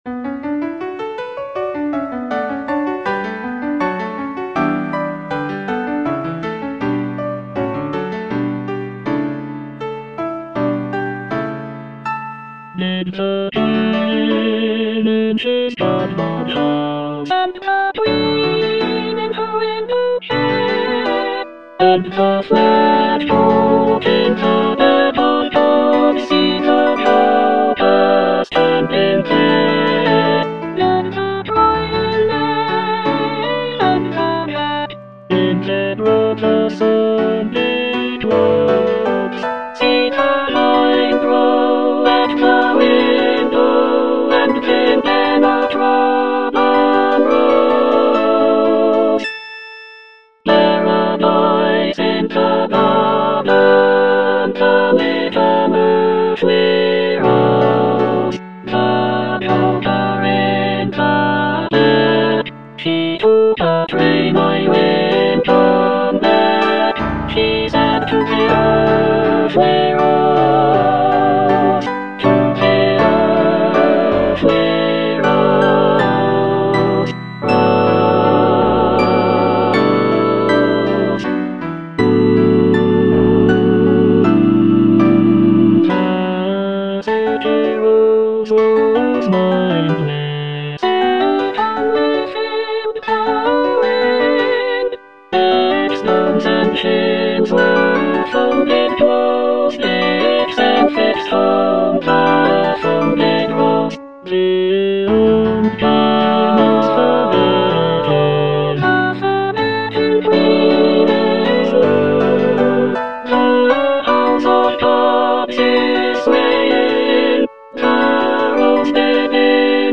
All voices